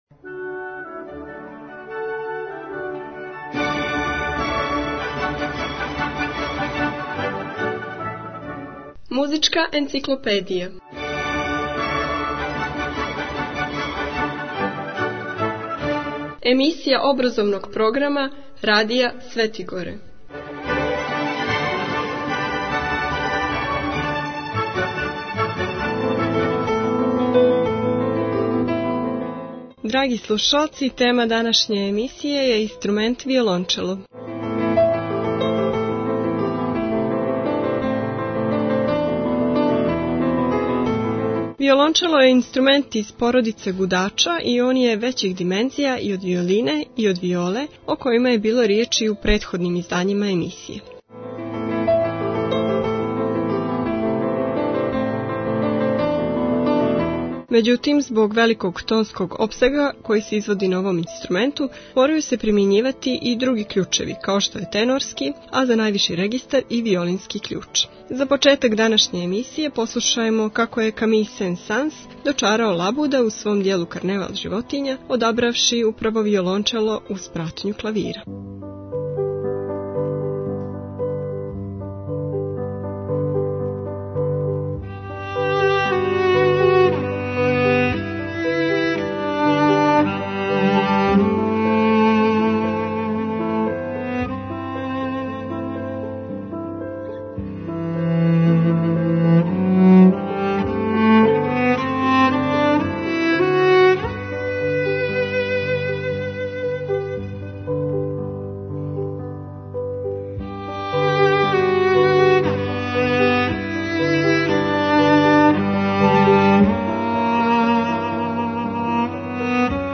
Албум: Muzicka enciklopedija Година: 2016 Величина: 33:40 минута (5.78 МБ) Формат: MP3 Mono 22kHz 24Kbps (CBR) У емисији "Музичка енциклопедија" можете чути инструмент виолончело и сазнати нешто више о њему.